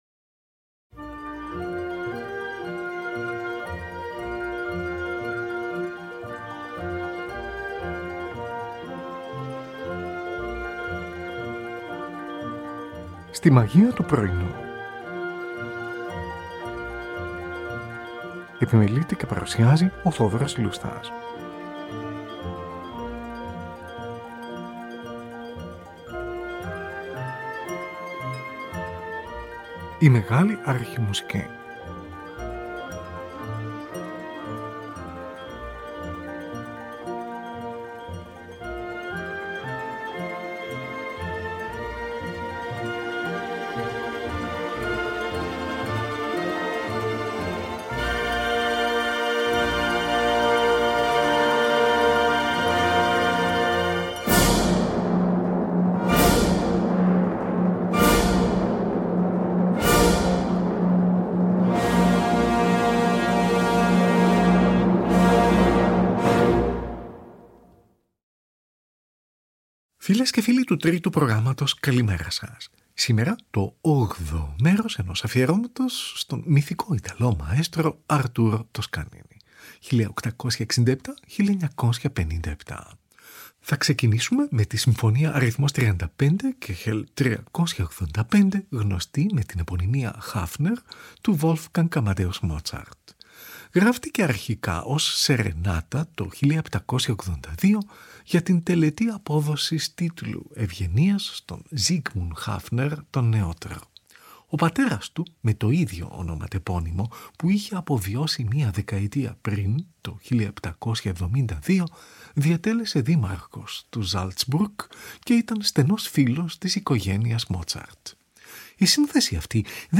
Τη Συμφωνική του NBC διευθύνει ο Arturo Toscanini. Ζωντανή ραδιοφωνική μετάδοση, στις 3 Νοεμβρίου 1946. Ludwig van Beethoven: Συμφωνία αρ.9, έργο 125: 1ο , 2ο & 3ο μέρος .
Zωντανή ραδιοφωνική μετάδοση από το Carnegie Hall της Νέας Υόρκης, στις 2 Δεκεμβρίου 1939 .